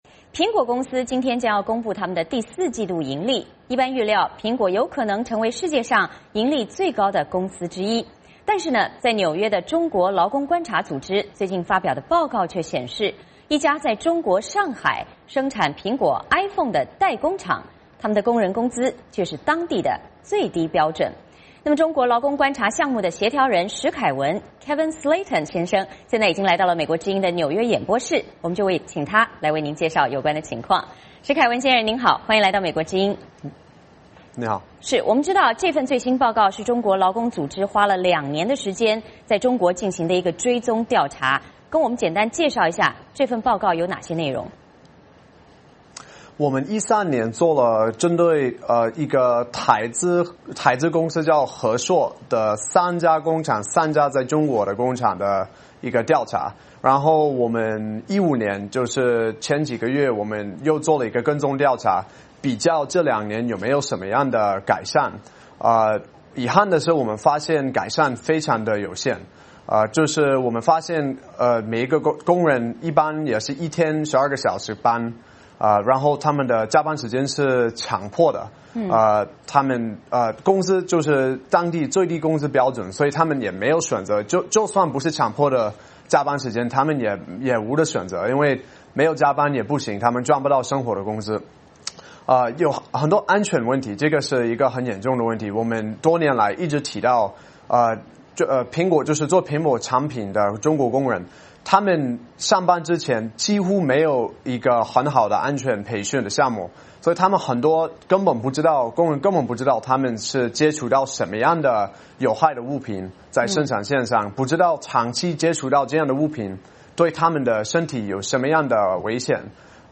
来到美国之音的纽约演播室，请他介绍有关情况。